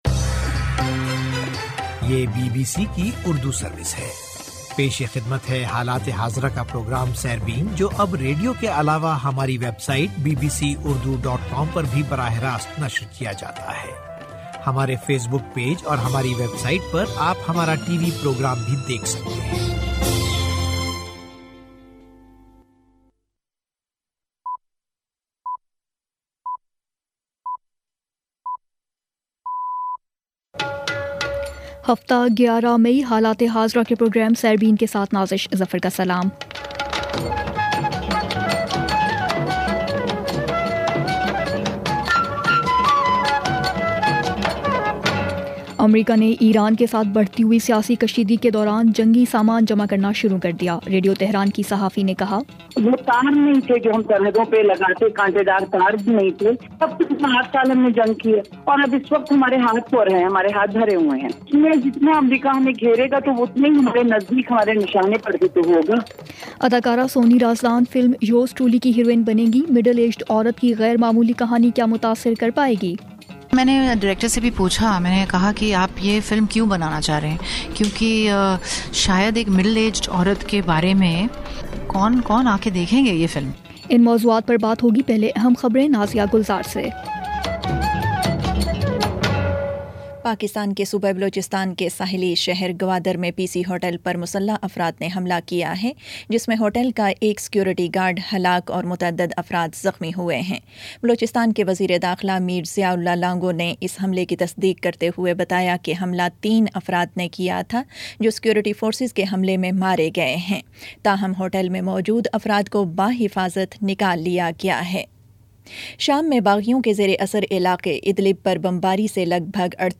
سنیچر 11 مئ کا سیربین ریڈیو پروگرام